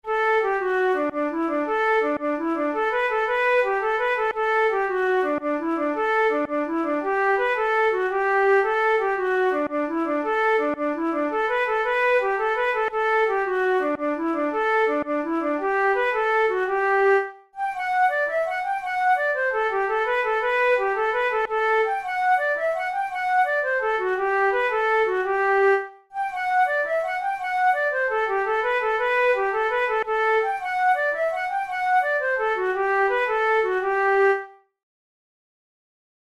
InstrumentationFlute solo
KeyG major
Time signature6/8
Tempo112 BPM
Jigs, Traditional/Folk
Traditional Irish jig